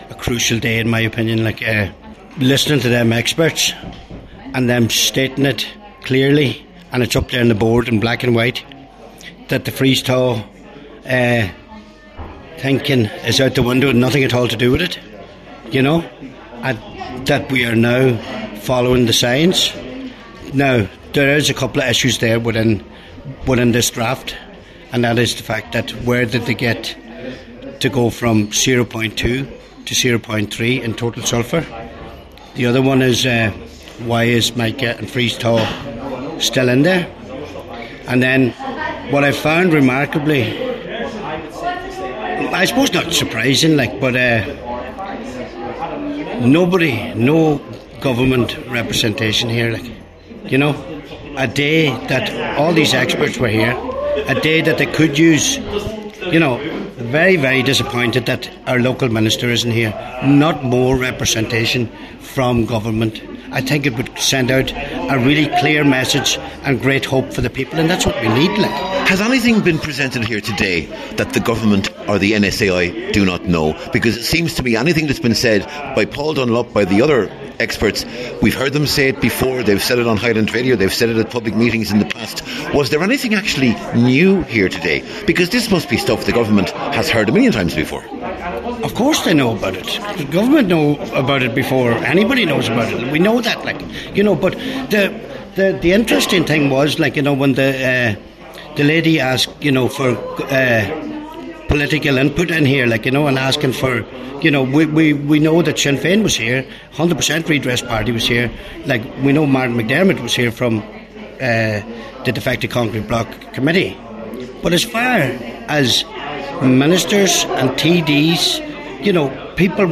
Cllr Dennis McGee was speaking in Burt this afternoon, where the 2025 Defective Block Conference took place.
Cllr McGee told Highland Radio News the conference was a vital one, as it laid out the scientific evidence in no uncertain terms………….